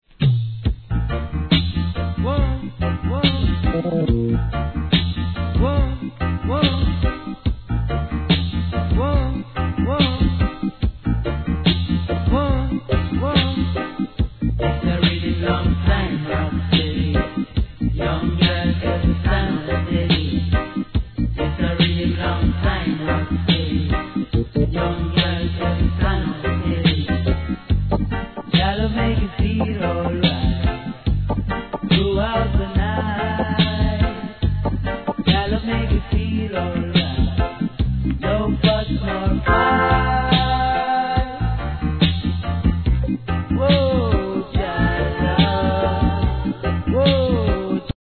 REGGAE
ピシリと引き締まったリズムに清涼感を感じさせるコーラスかGOOD!